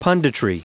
Prononciation du mot punditry en anglais (fichier audio)
Prononciation du mot : punditry